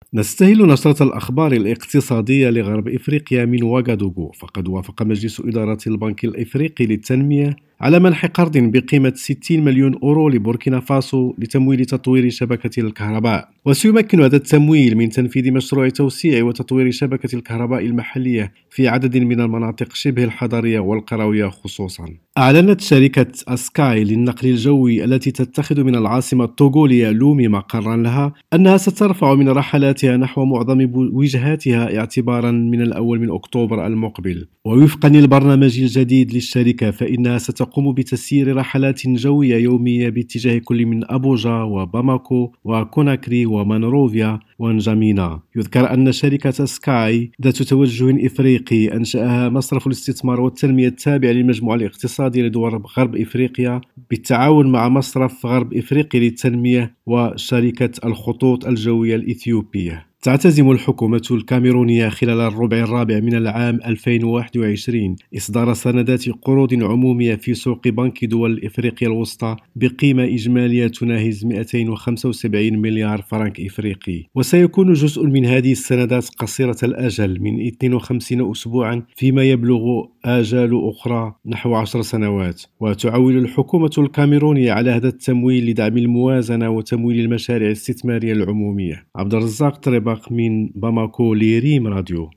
النشرة الاقتصادية لغرب افريقيا